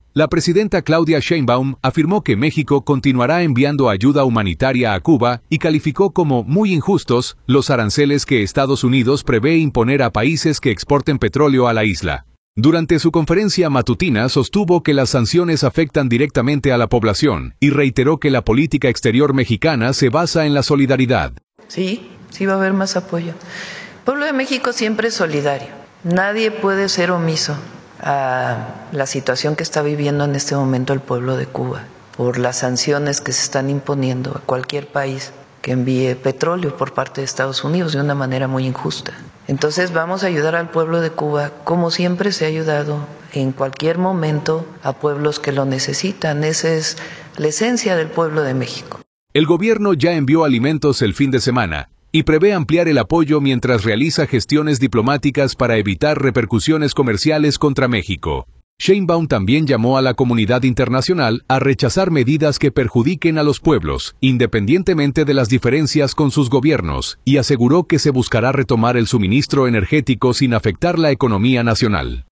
La presidenta Claudia Sheinbaum afirmó que México continuará enviando ayuda humanitaria a Cuba y calificó como “muy injustos” los aranceles que Estados Unidos prevé imponer a países que exporten petróleo a la isla. Durante su conferencia matutina, sostuvo que las sanciones afectan directamente a la población y reiteró que la política exterior mexicana se basa en la solidaridad.